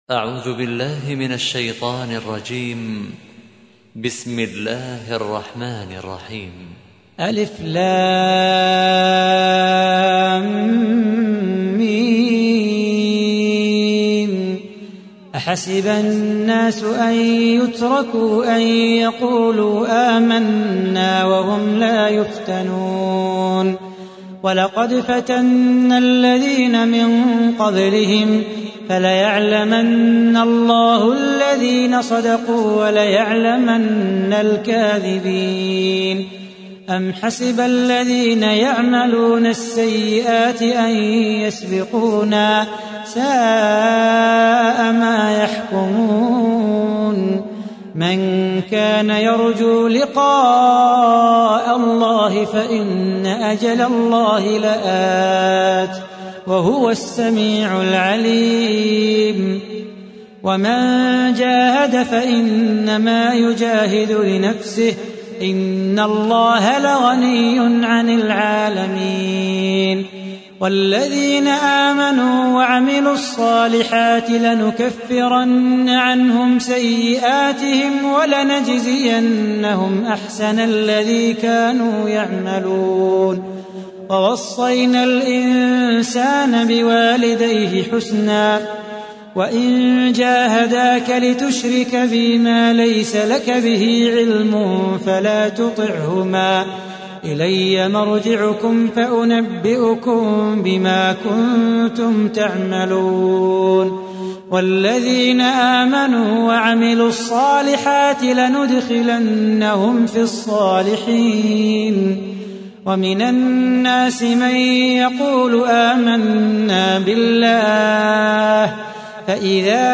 تحميل : 29. سورة العنكبوت / القارئ صلاح بو خاطر / القرآن الكريم / موقع يا حسين